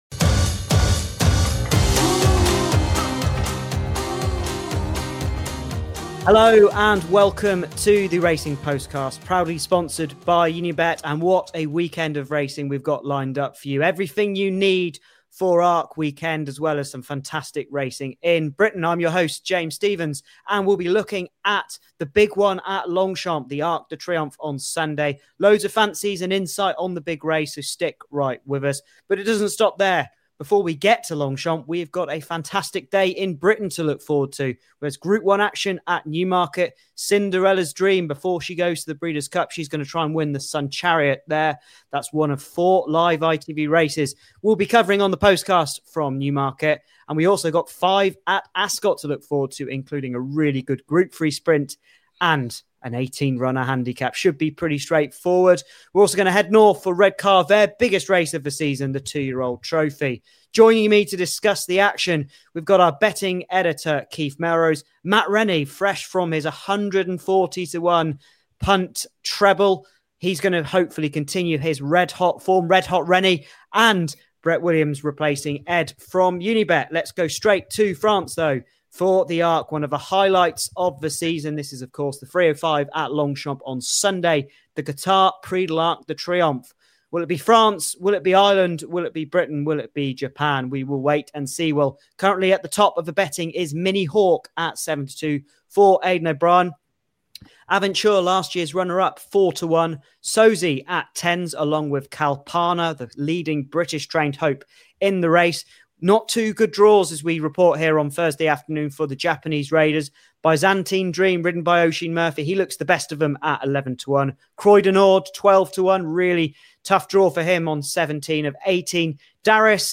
Join us for the latest episode of the Racing Postcast as our team dissects a huge weekend of racing.